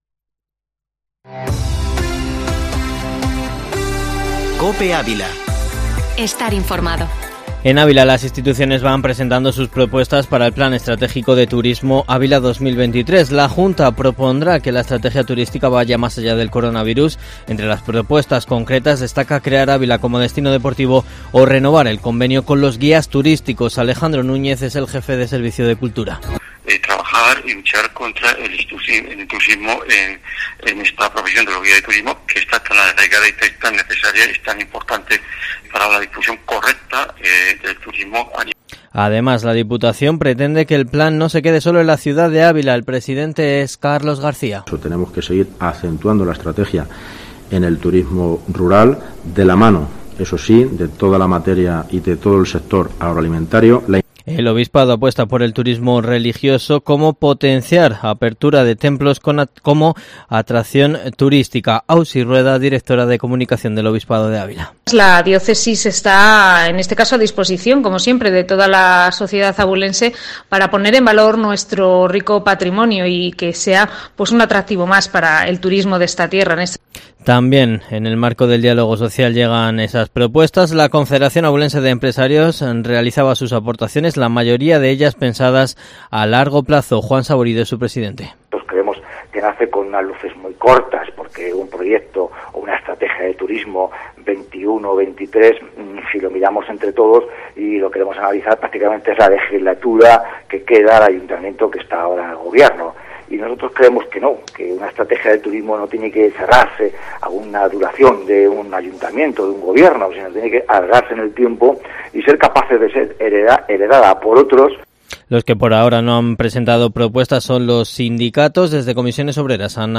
Informativo matinal Herrera en COPE Ávila 18/11/2020